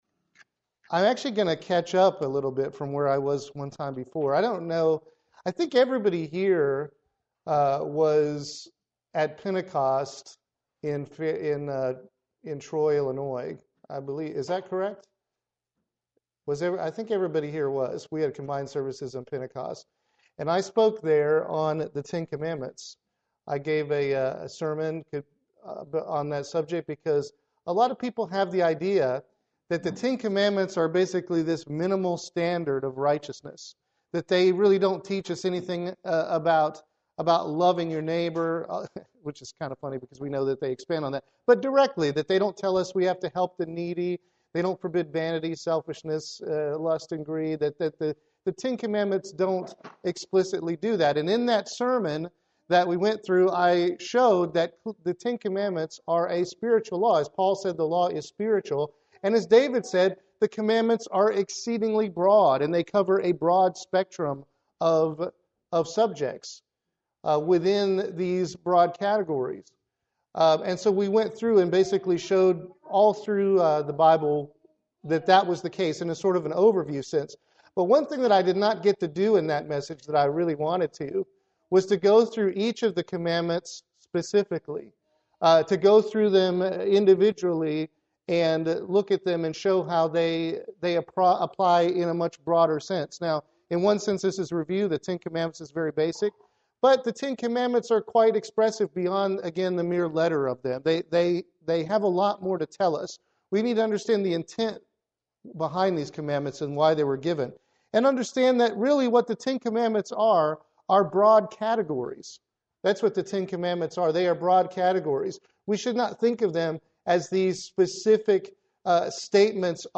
Sermons
Given in Columbia - Fulton, MO